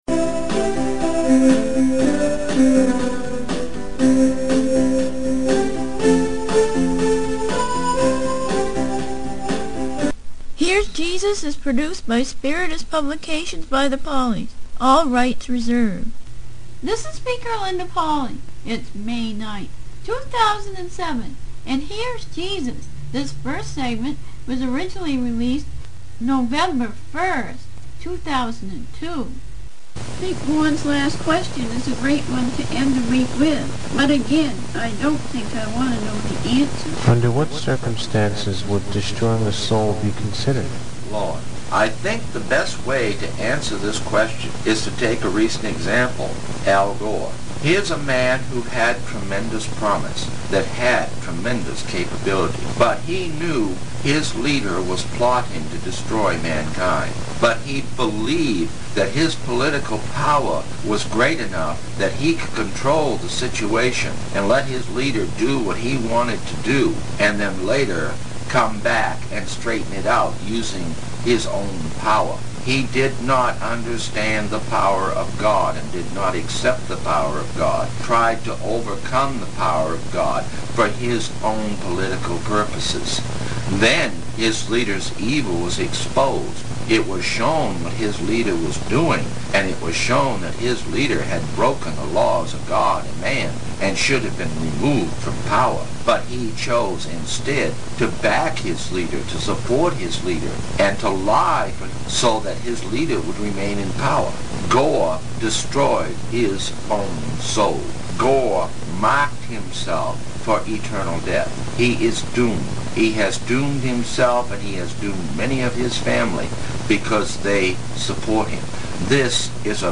Interviews With
Channeled Through Internationally Known Psychic